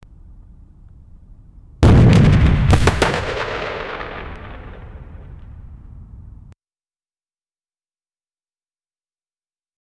Basketball announcer 0:10 Created Jun 14, 2025 4:02 PM Voice over voiceover who plays football. 0:25 Created Mar 1, 2025 6:19 PM a reporter saying - When our batting collapses for the 99th time this season 0:10 Created Jun 30, 2025 1:49 PM
a-reporter-saying---when-skyujlbq.wav